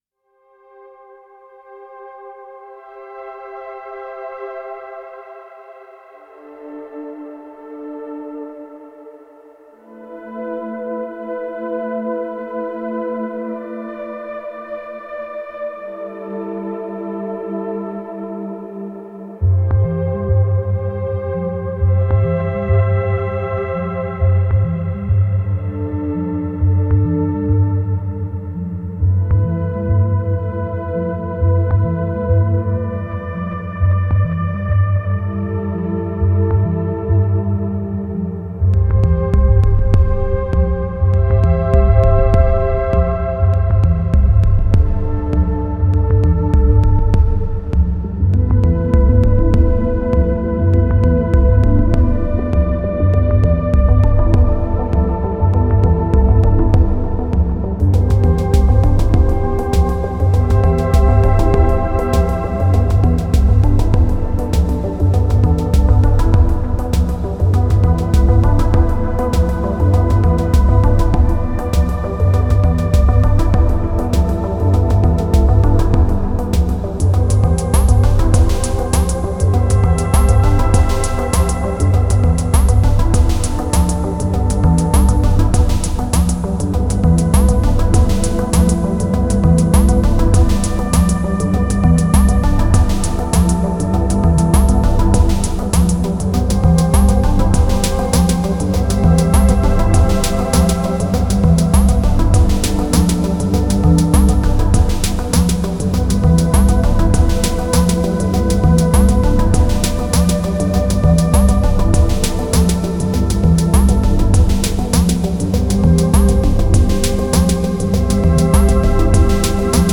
Genre: Techno Ambient.